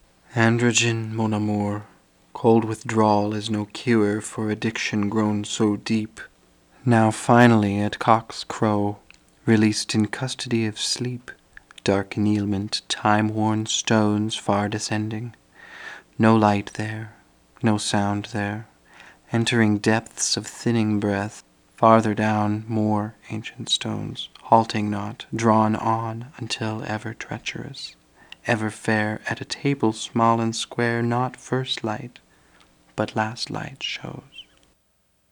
Processing: stereo + KS 363/135, 363/181, 271/181, 363/181, 363/305, 363/152, 363/181, F = 980/990